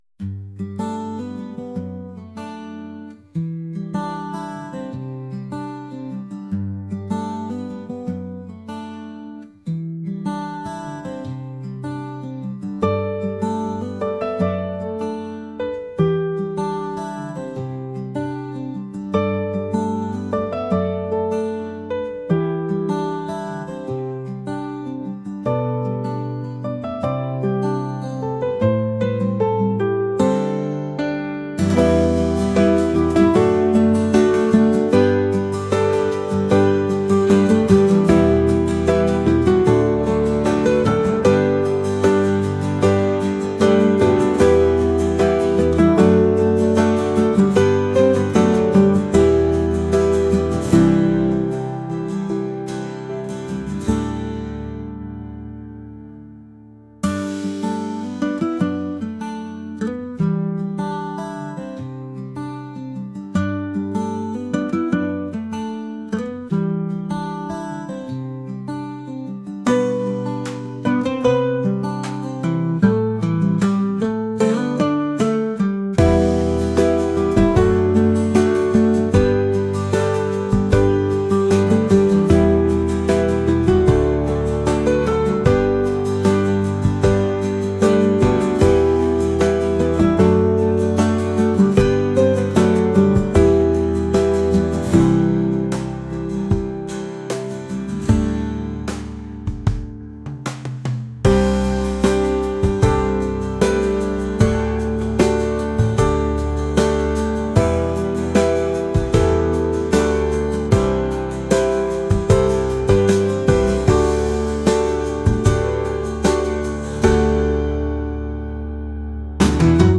acoustic | pop | folk